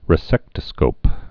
(rĭ-sĕktə-skōp)